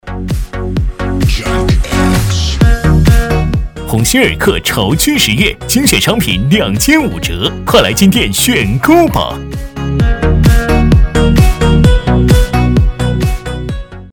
男-070号-列表页
促销 超市促销样音 play stop mute max volume repeat